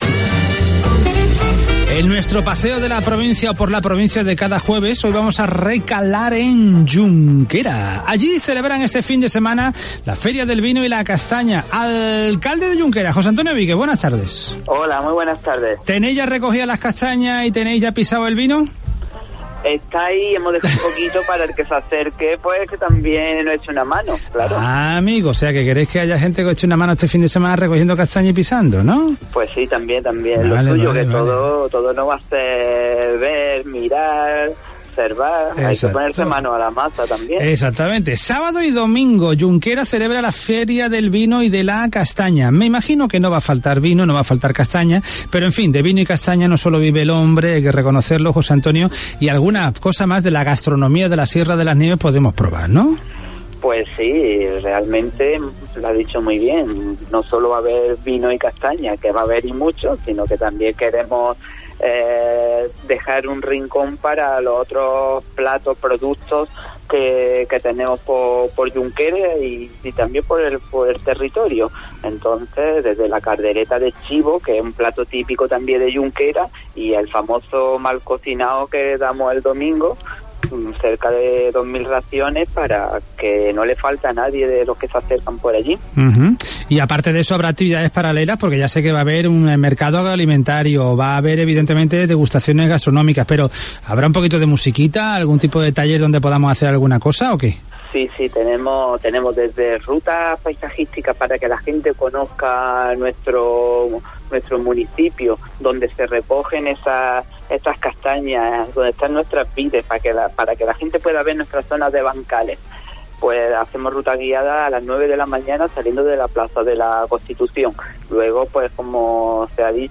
'Los que nos visiten podrán conocer nuestro bosques de castaños, probar los mejores platos elaborados con castañas o comprar una amplia variedad de productos artesanos' ha destacado el alcalde de Yunquera, José Antonio Víquez, en Buenas Tardes Málaga.